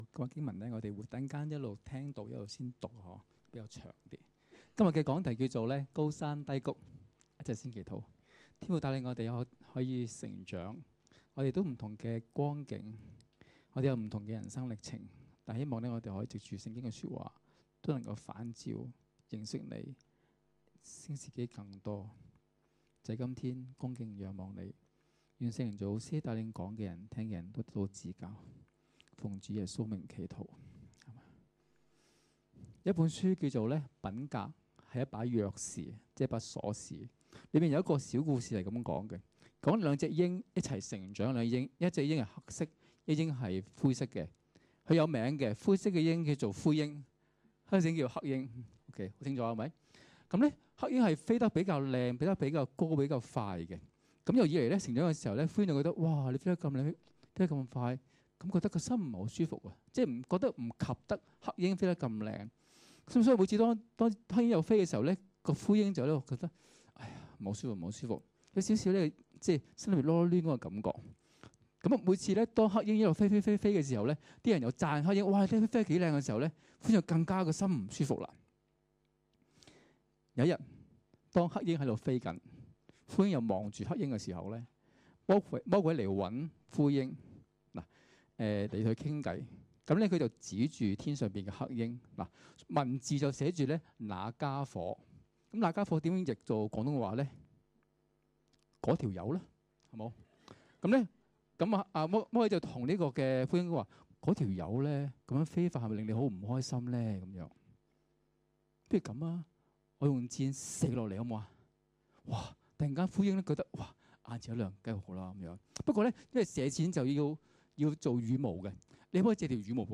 2016年6月25日及26日崇拜